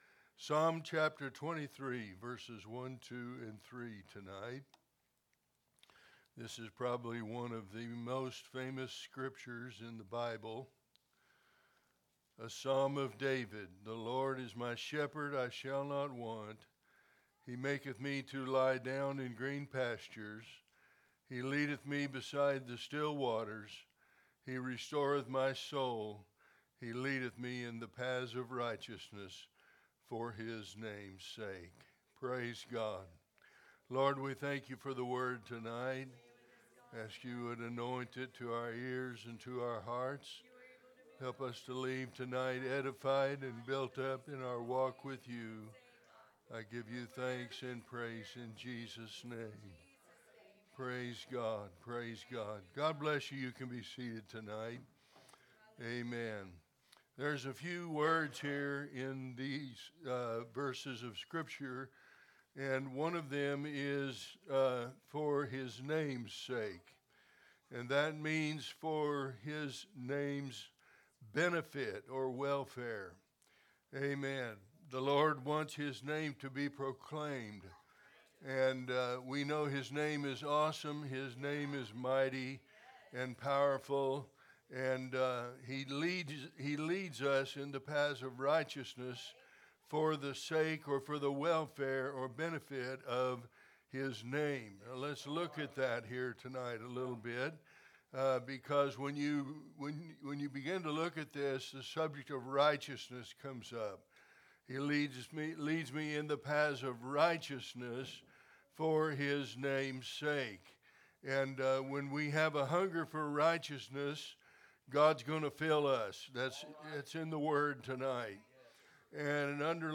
Wednesday Message